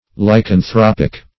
Lycanthropic \Ly`can*throp"ic\, a. Pertaining to lycanthropy.